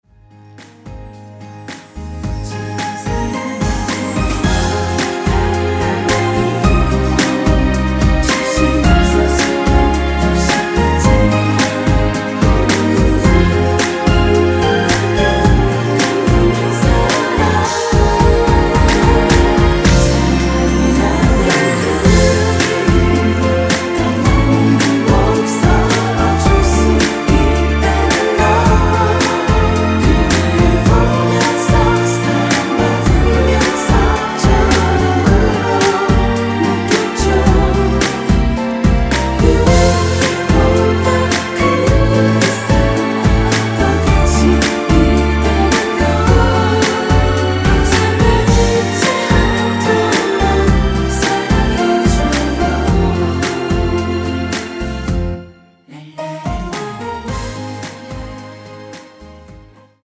원키 코러스 MR에서 사운드 마커 포함된 전체 미리듣기 가능 하겠습니다.
원키에서(-1)내린 코러스 포함된 MR입니다.
전주가 너무길어 시작 Solo 부분22초 정도 없이 제작 하였으며
엔딩부분이 페이드 아웃이라 엔딩을 만들어 놓았습니다.(미리듣기 참조)
Ab
앞부분30초, 뒷부분30초씩 편집해서 올려 드리고 있습니다.